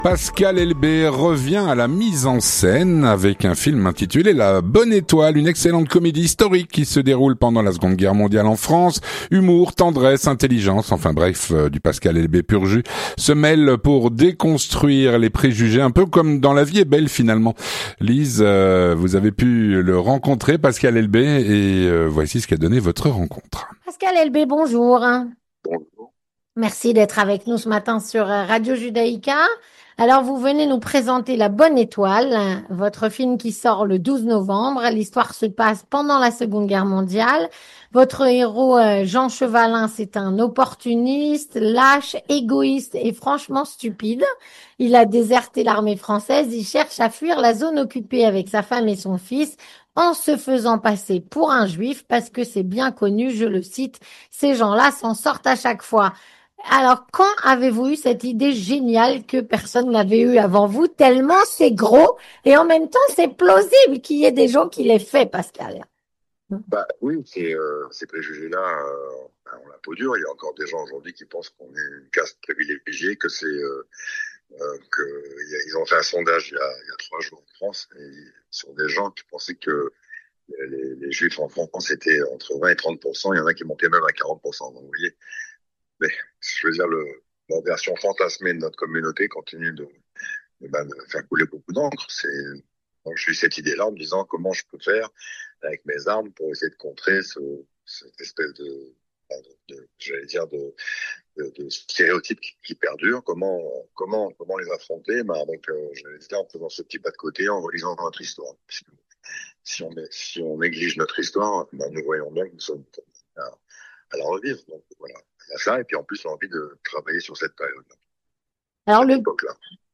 Rencontre avec... - Pascal Elbé reprend la mise en scène avec "La bonne étoile".